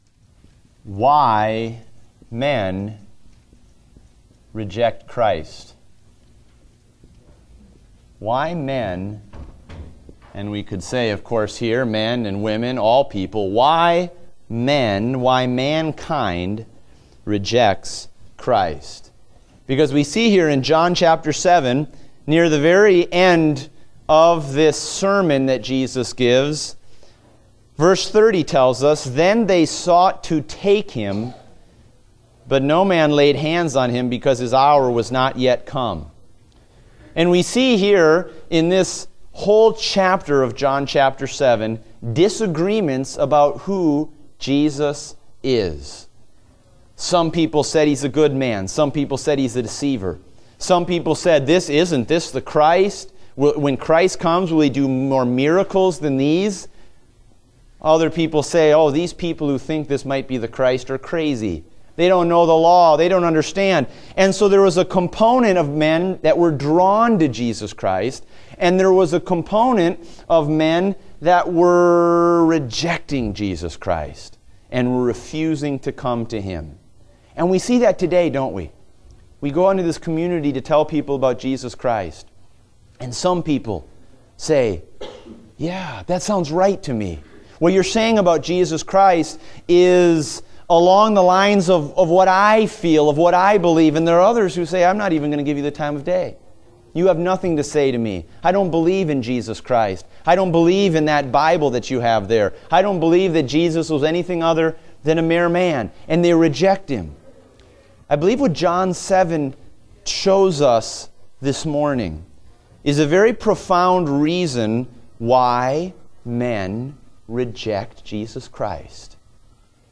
Date: September 4, 2016 (Adult Sunday School)